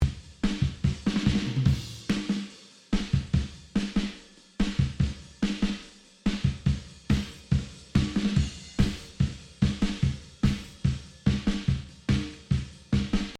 Here are two samples of drums from the upcoming EP to illustrate. The difference may be subtle on ear buds or your phone’s speakers but noticeable enough through larger speakers.
Drums acoustic
drums-acoustic-only.mp3